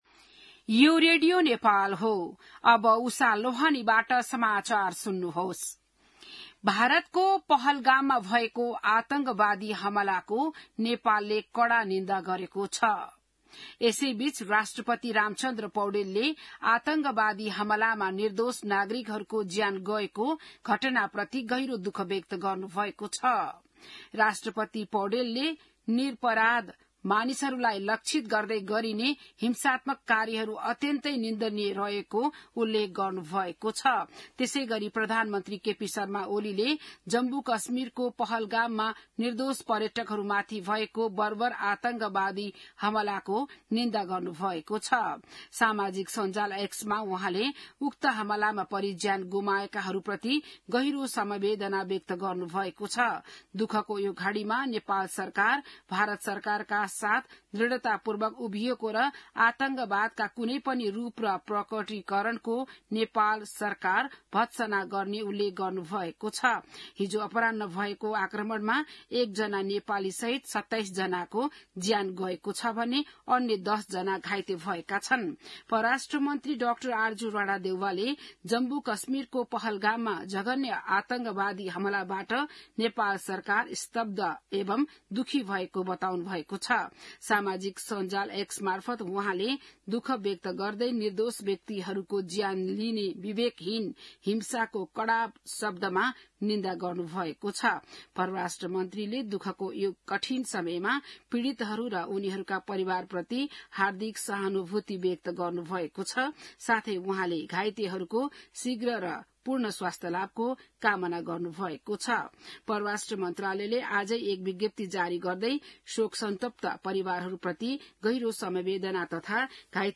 बिहान १० बजेको नेपाली समाचार : १० वैशाख , २०८२